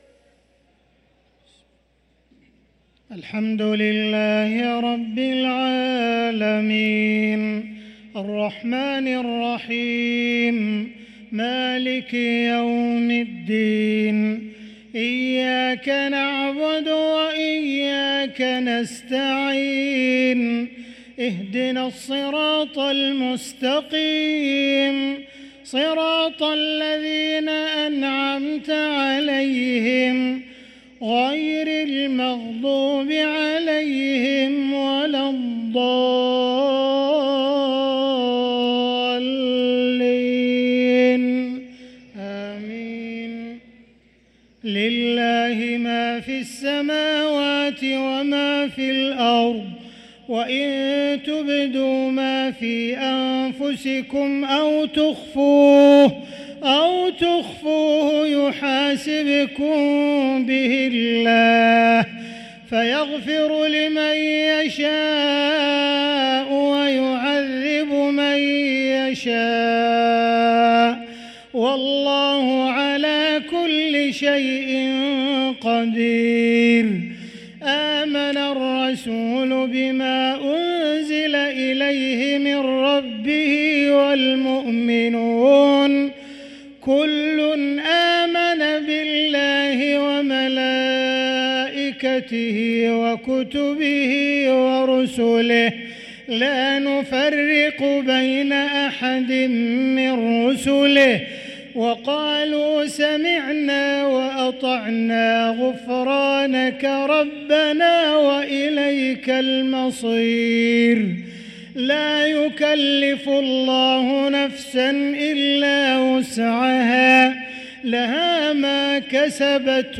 صلاة العشاء للقارئ عبدالرحمن السديس 11 رجب 1445 هـ
تِلَاوَات الْحَرَمَيْن .